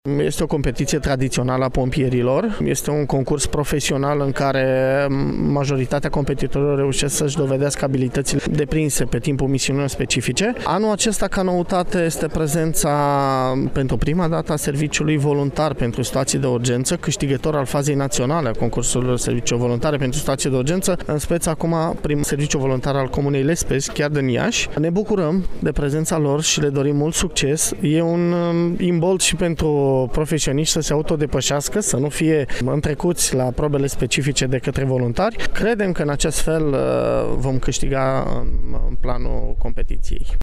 Noul şef al Inspectoratului General pentru Situaţii de Urgenţă, Daniel-Marian Dragne: